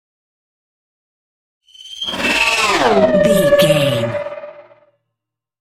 Sci fi vehicle whoosh large
Sound Effects
dark
futuristic
whoosh